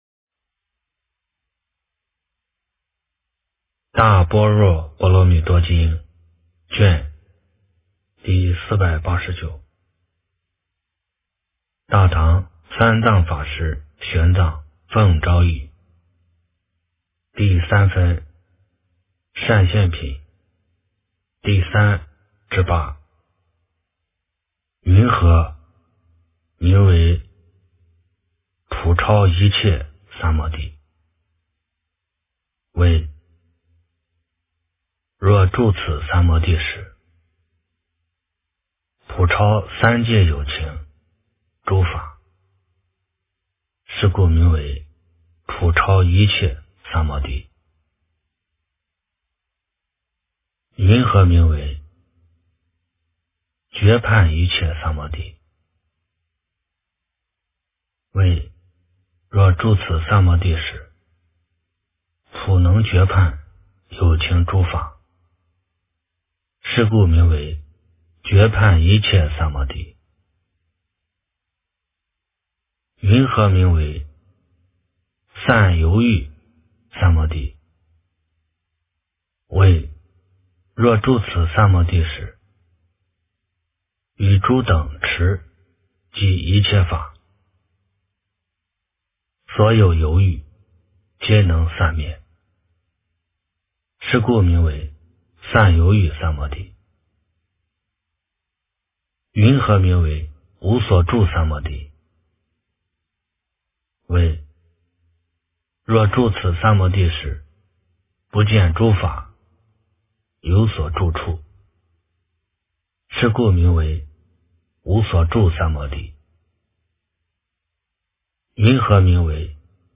大般若波罗蜜多经第489卷 - 诵经 - 云佛论坛